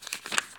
x_enchanting_scroll.1.ogg